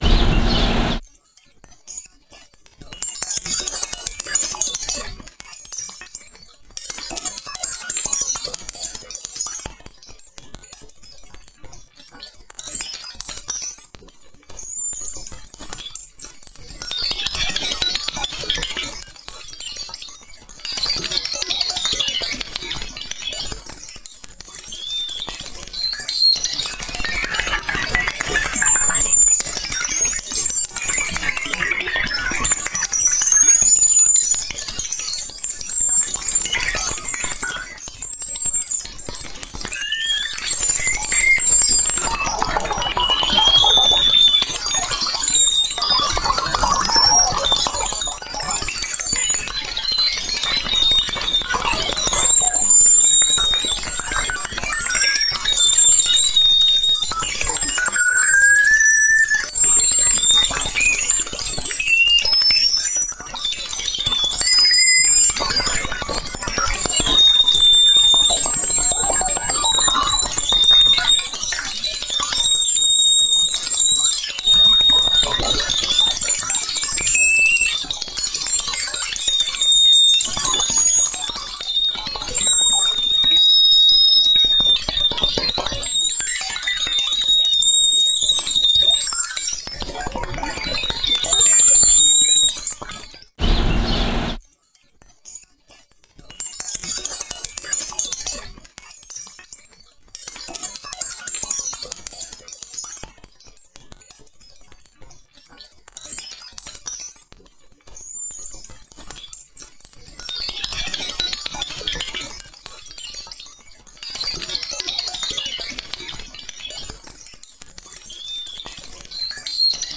• Instrumentation: experimental electronica